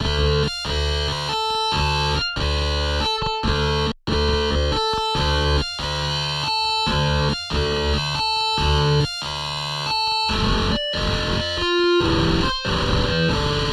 原声吉他系列2之4流行乐110
它包括2个110 BPM的流行音乐吉他循环和2个90 BPM的嘻哈吉他循环。
Tag: 110 bpm Acoustic Loops Guitar Acoustic Loops 1.47 MB wav Key : Unknown